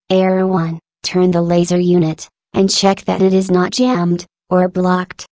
glados_dreame_voice_pack_customized